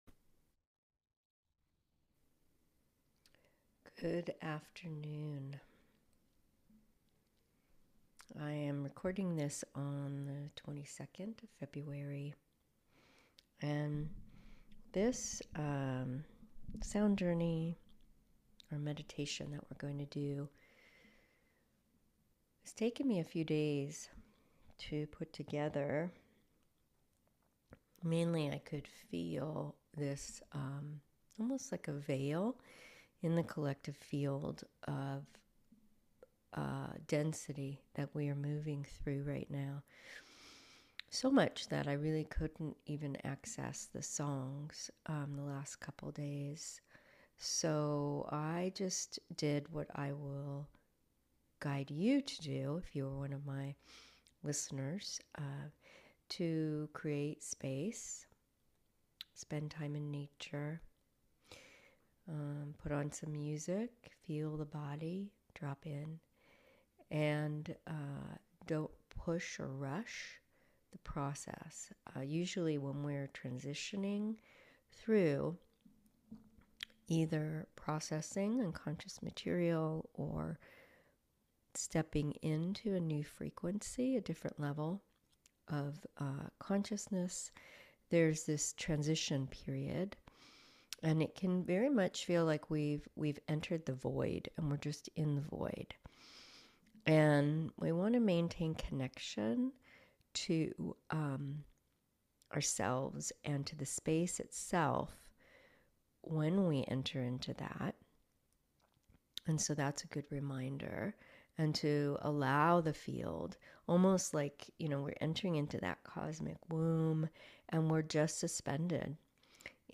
Activating the Heart – Guided Meditation/ Embodied Coherence Experience